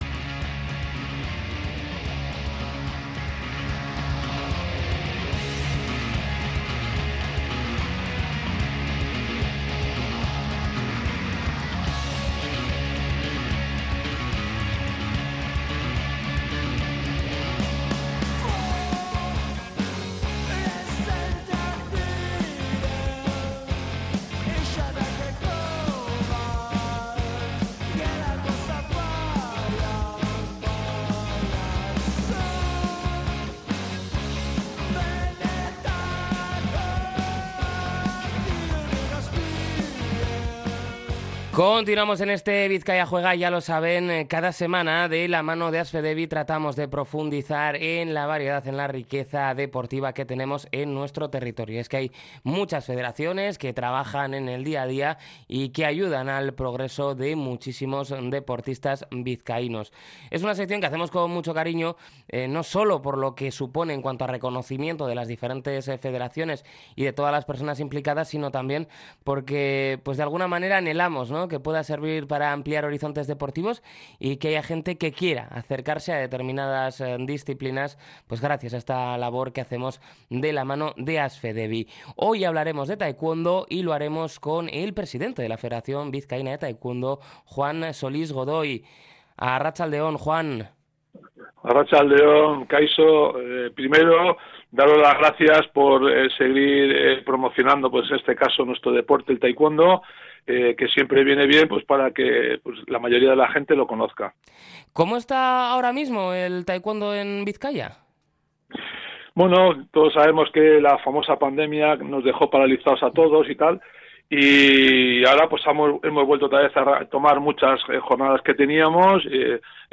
Podcast Deportes